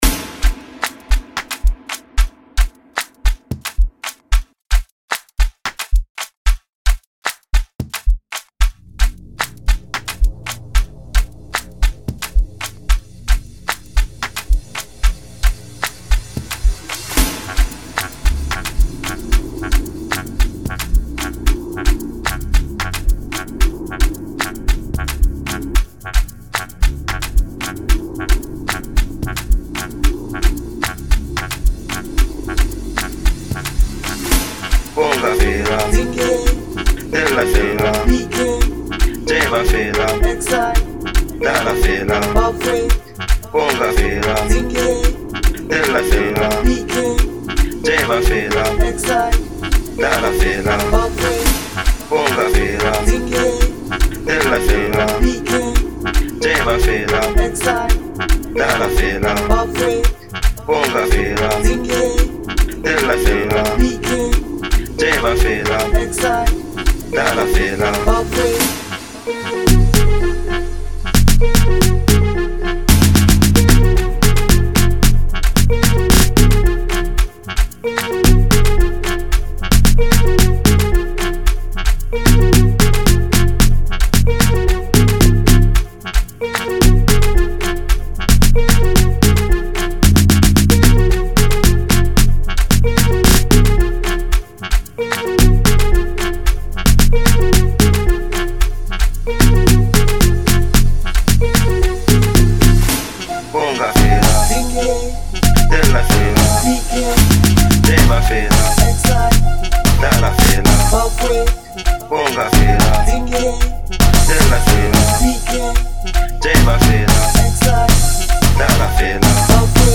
05:34 Genre : Amapiano Size